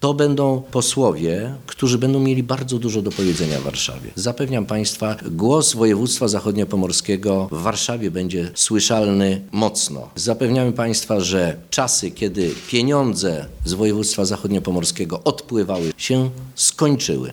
– mówił Michał Jach podczas powyborczej konferencji w Stargardzie.
jach konferencja.mp3